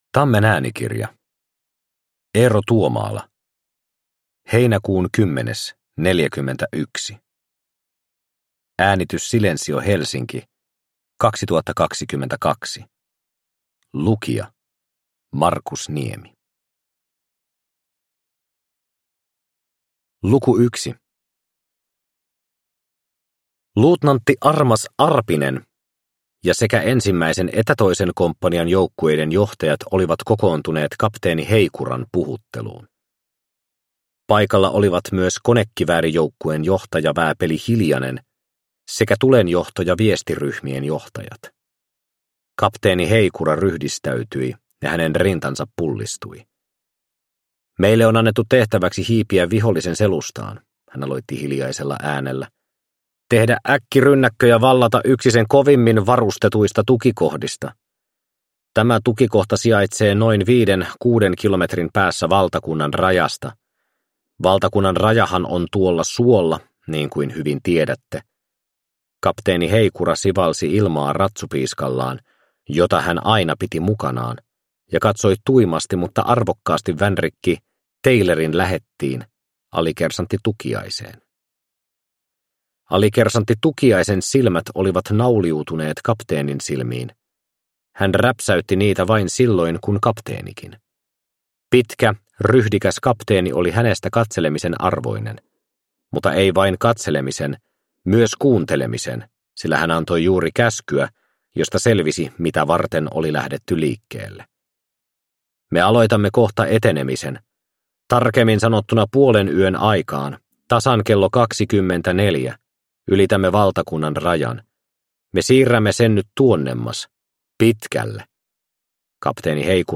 Heinäkuun kymmenes -41 – Ljudbok – Laddas ner